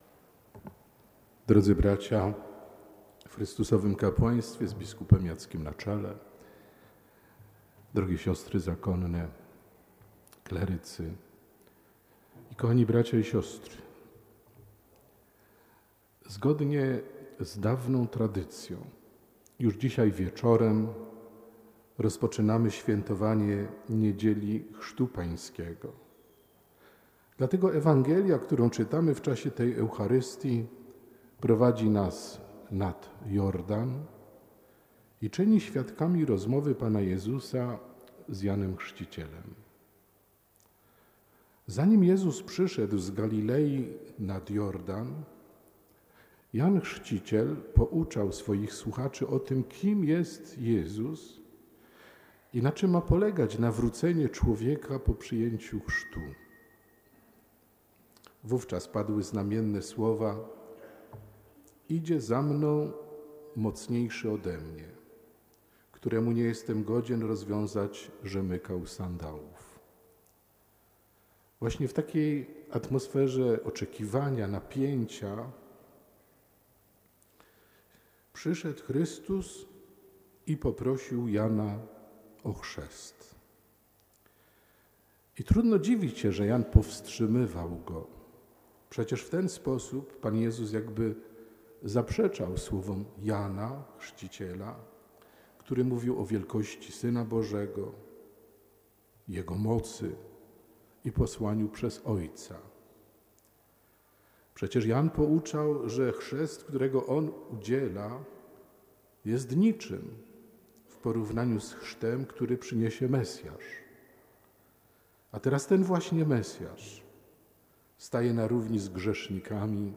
Przyszłość Kościoła nie zależy od tych, którzy przystosowują się do danej chwili, ani od tych, którzy jedynie krytykują innych, a samych siebie uważają za nieomylnych – mówił abp Józef Kupny we wrocławskiej katedrze, przewodnicząc Mszy św. w intencji śp. Benedykta XVI.
homilia-Abp.-Kupnego.mp3